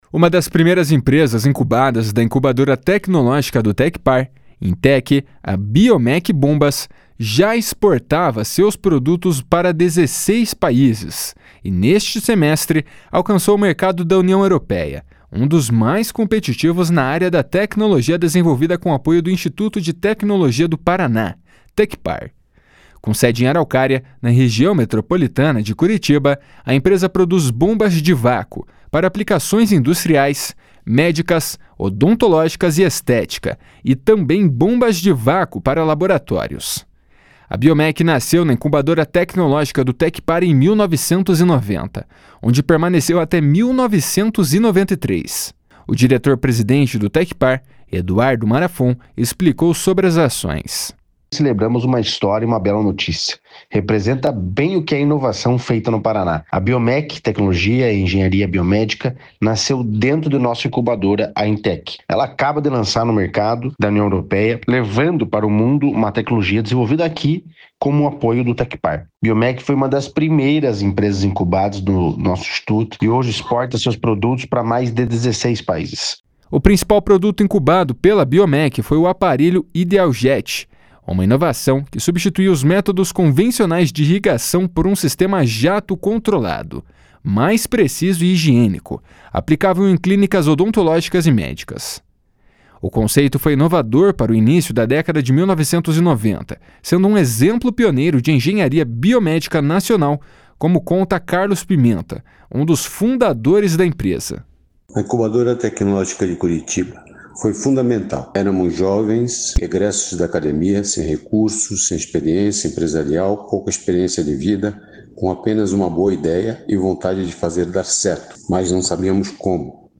O diretor-presidente do Tecpar, Eduardo Marafon, explicou sobre as ações.